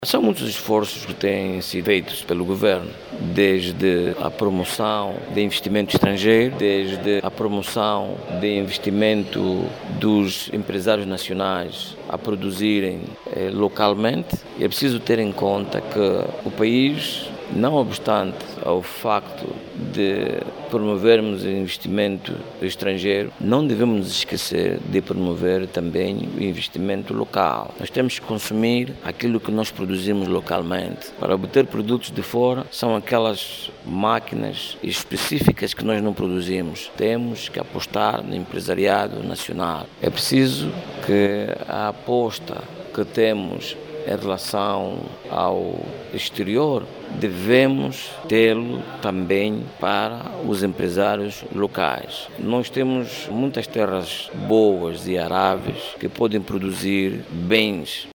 na 3.ª edição do Angola Economic Fórum 2025